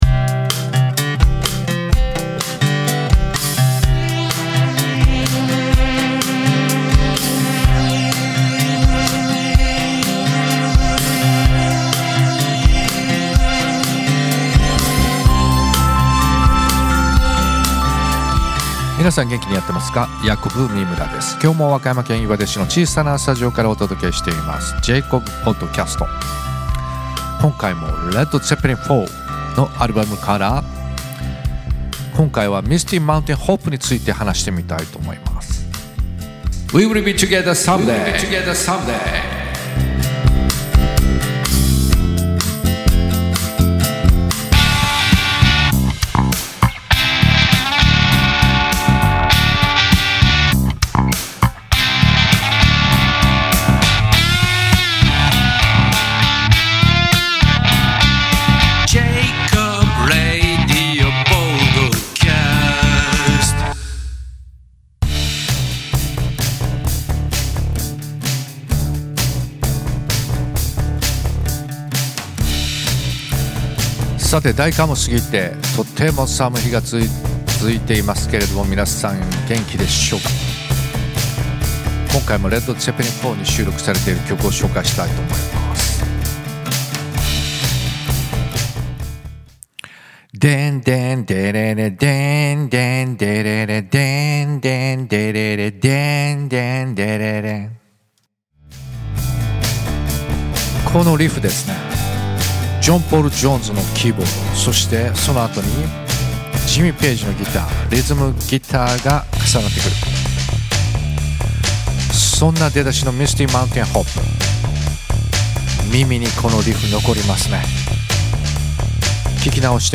・エレクトリックギター
・キーボード（keys、ベース）
・ボーカル
・ドラム（GarageBand）